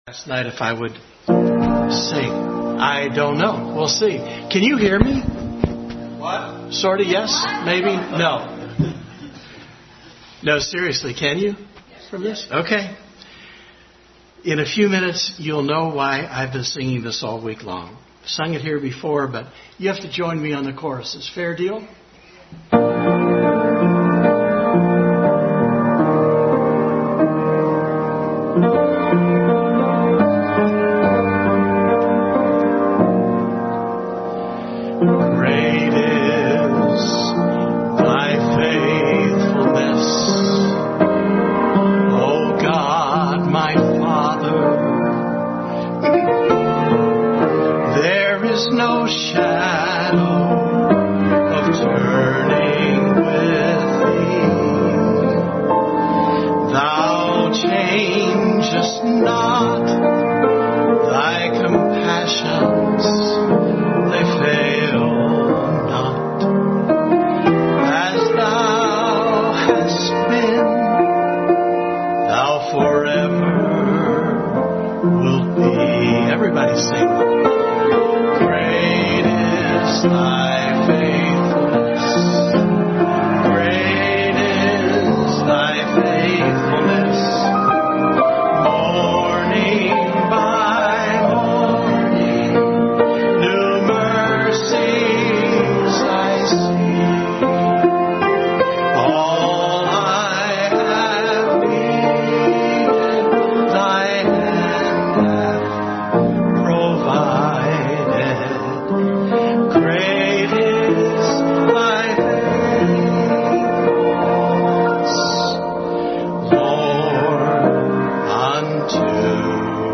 Bible Text: Lamentations 3:19-26, 1 Corinthians 1:4-9, Revelations 21:1-7 | Family Bible Hour sermon 2/24/19 “The Faithfulness of God”.